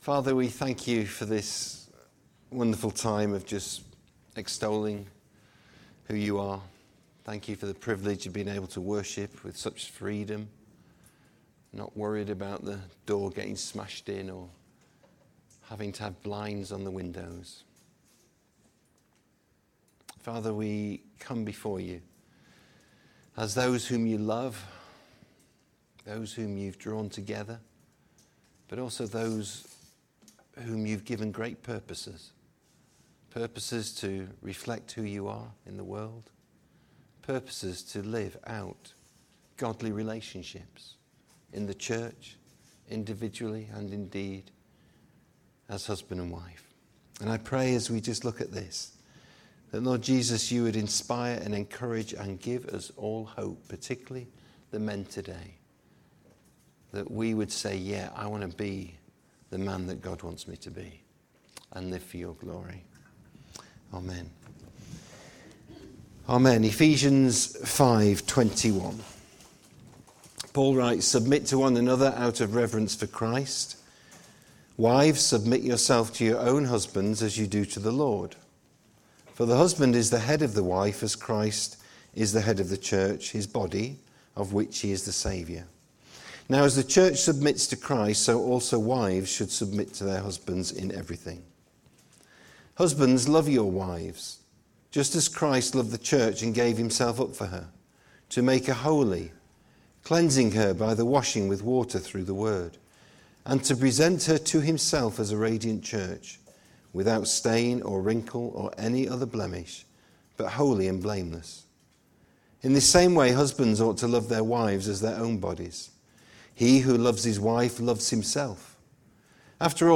This sermon focuses on the role of the husband in a marriage. Husbands are instructed to lead their wives offering protection, provision, nurture and love.